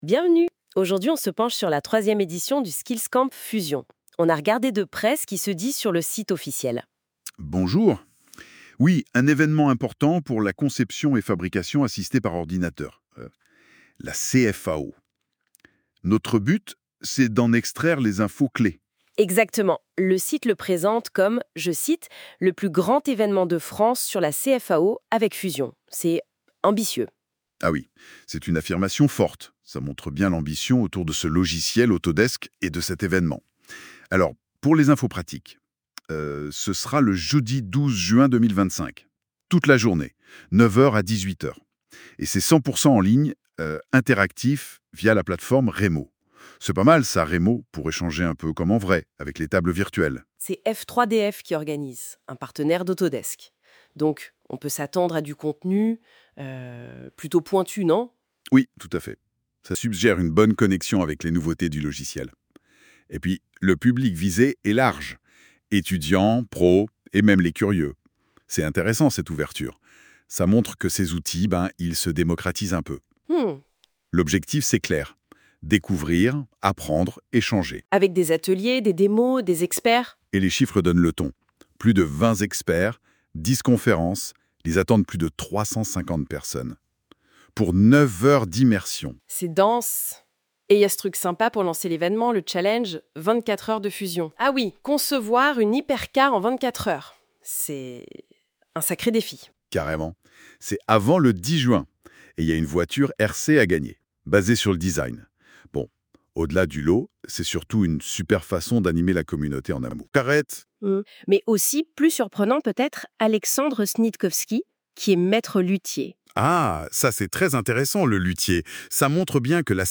[!Success]Ecoutez la conversation !